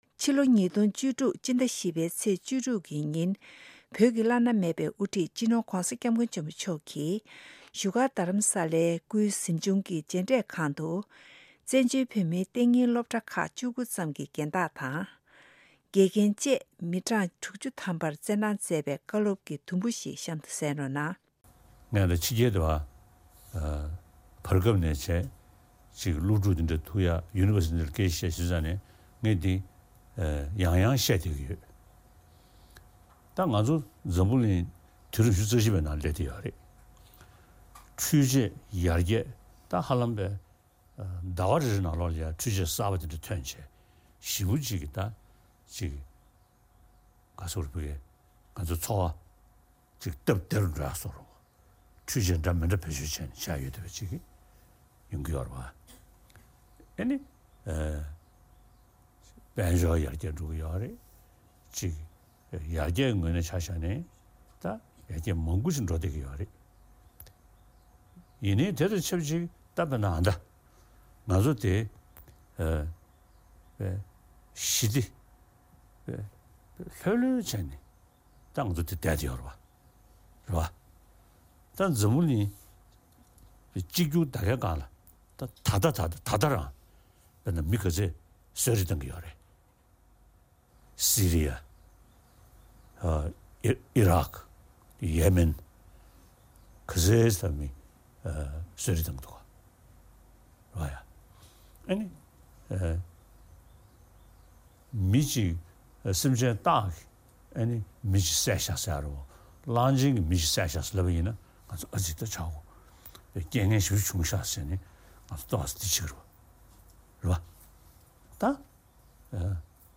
Dalai Lama Talks To Tibetan School Prefects and Teachers in Dharamsala Part 1